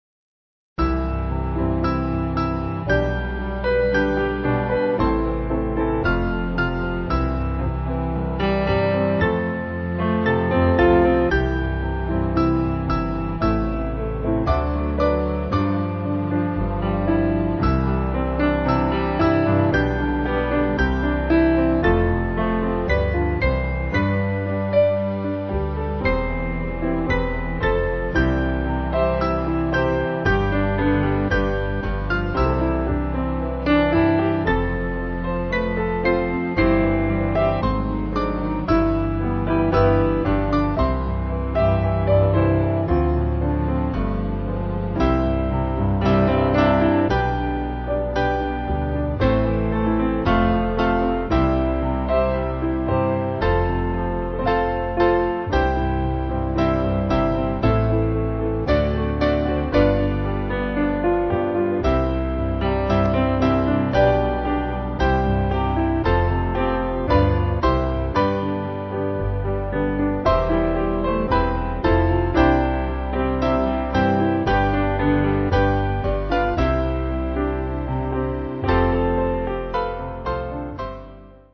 Mainly Piano